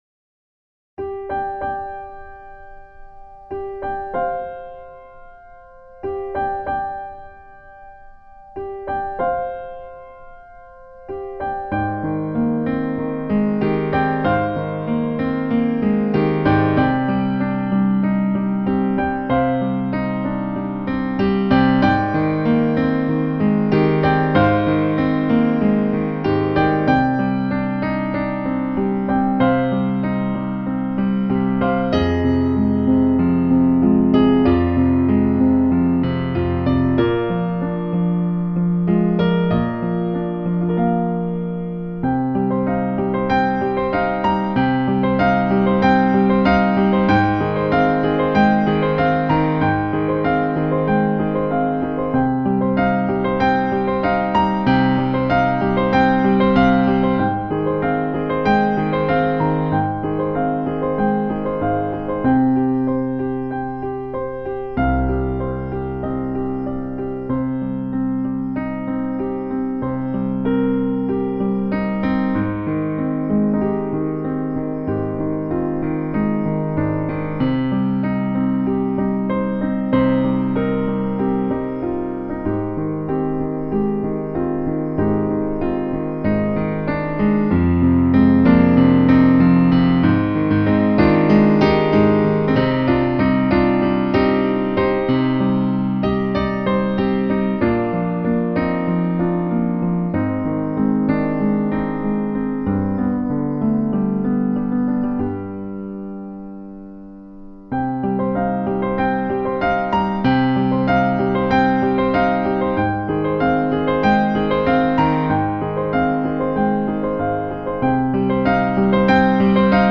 here is the piano track for you to sing your heart out to.